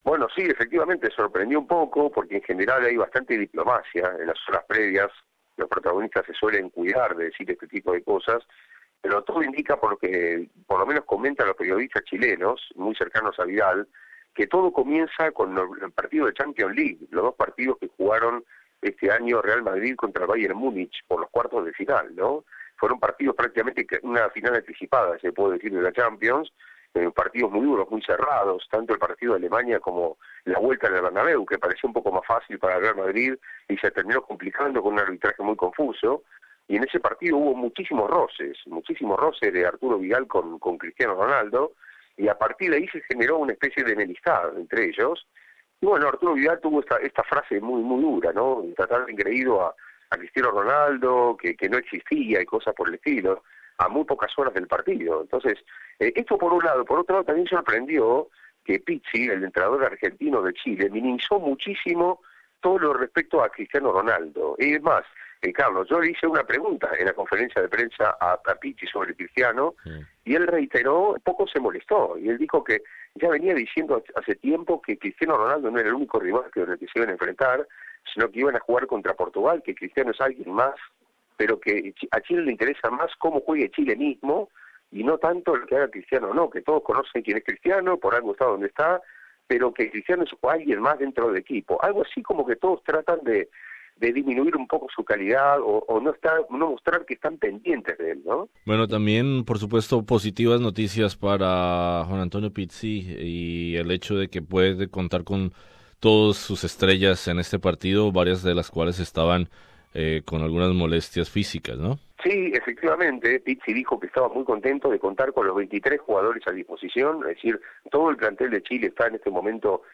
De esto nos habla precisamente nuestro corresponsal en Rusia